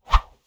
Close Combat Swing Sound 31.wav